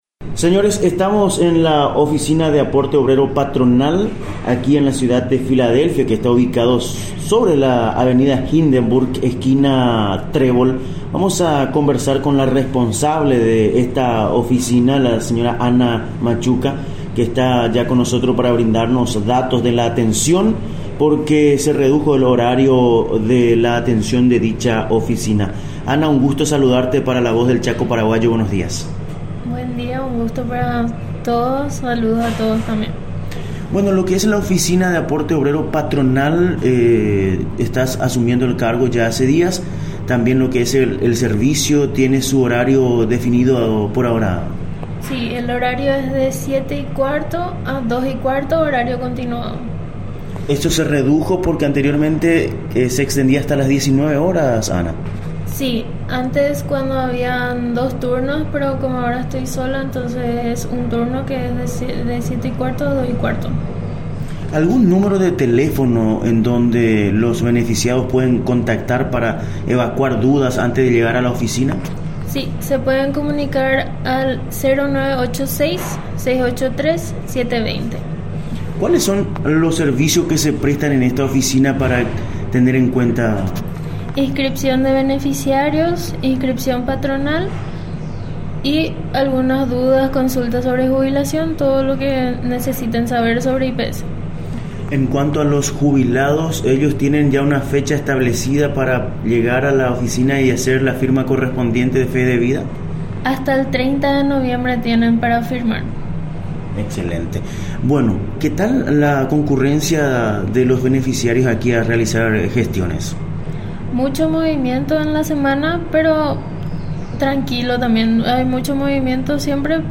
Entrevistas / Matinal 610
Estudio Central, Filadelfia, Dep. Boquerón